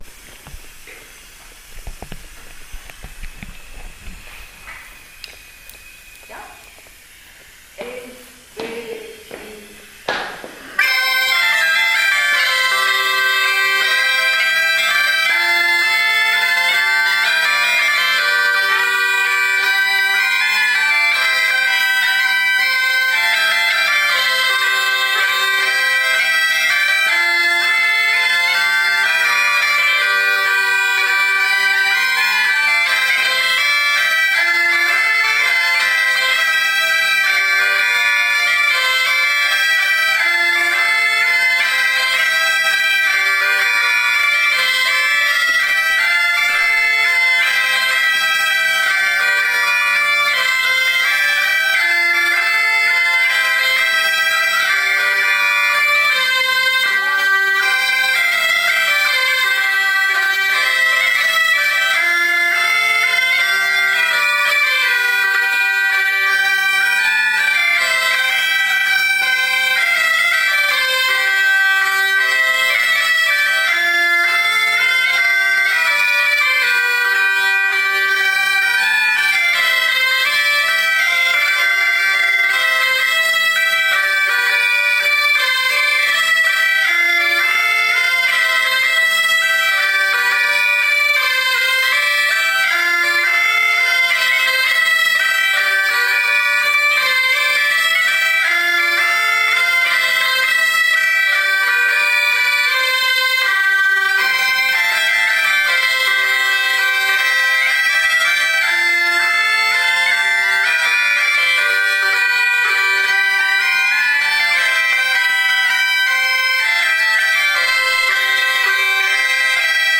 Doedelzakcursus
(en dat was heel beschaafd met slechts 3 spelers.